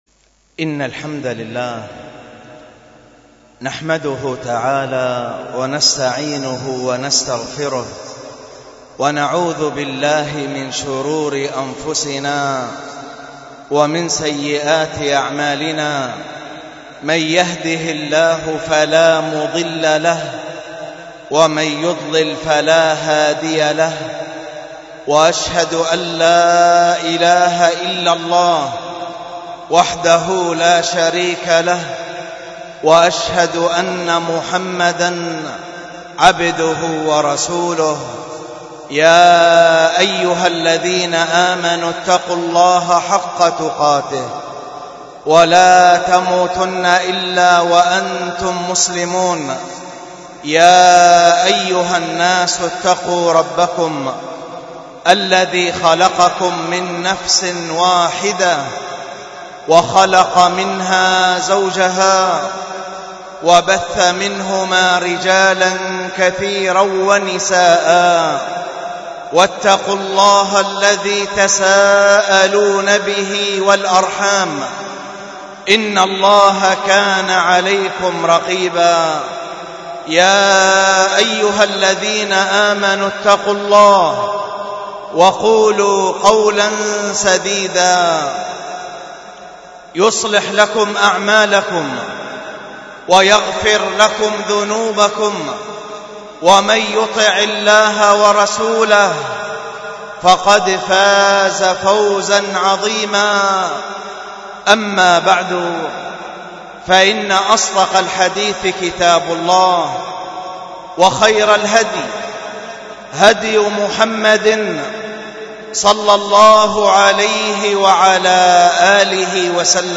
الخطبة